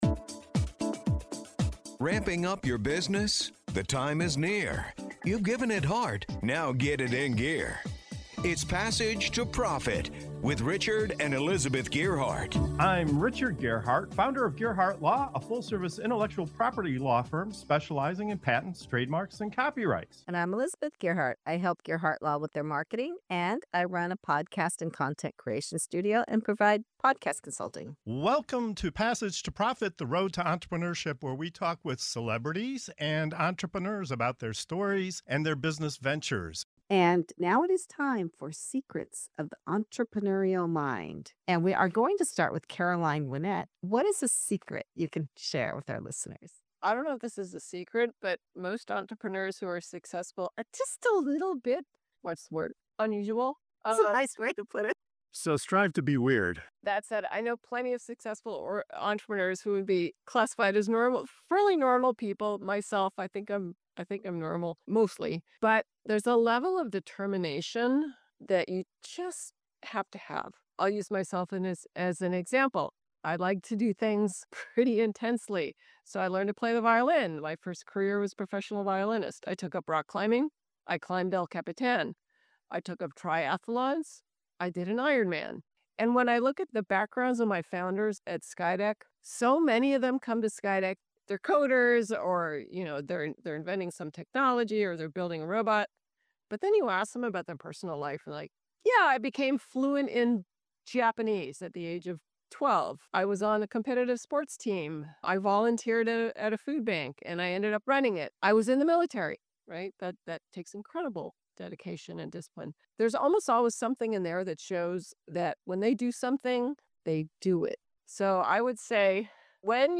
In this energizing segment of "Secrets of the Entrepreneurial Mind", our panel of experts and founders open up and share the unexpected traits, habits, and mindsets that fuel success. From embracing your inner “weird,” to tapping into support systems and strategic networking, to the surprising power of sleep and personal rituals, each guest reveals a candid secret that entrepreneurs rarely talk about.